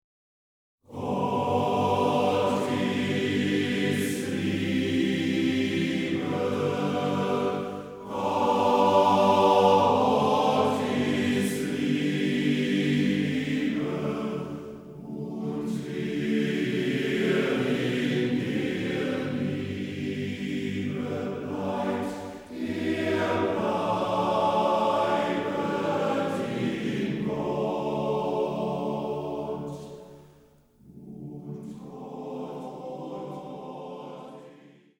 Orchester, Instrumentalsolisten